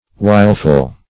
Wileful \Wile"ful\, a.